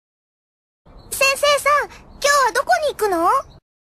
Stimmen der Hauptcharaktere
Seiyuu: Nana Mizuki (Kururu, Bottle Fairy)